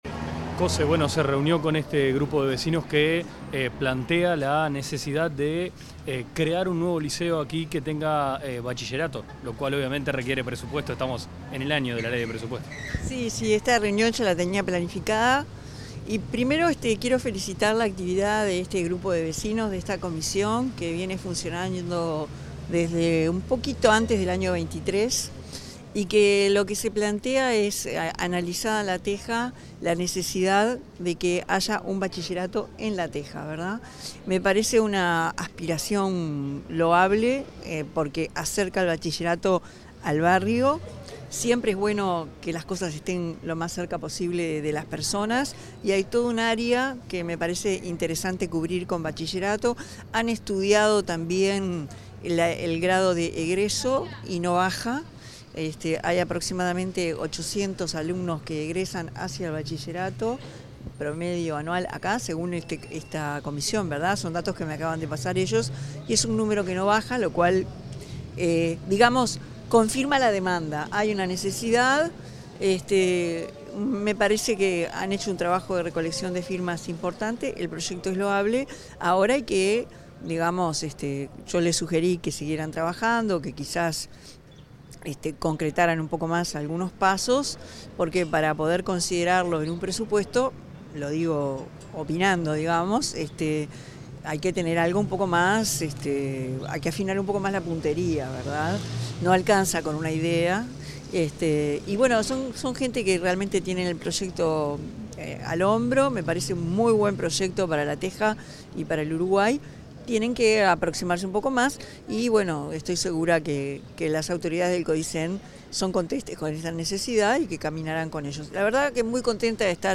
Declaraciones de la presidenta en ejercicio Carolina Cosse
La presidenta de la República en ejercicio, Carolina Cosse, dialogó con la prensa tras la reunión que mantuvo con integrantes de la comisión vecinal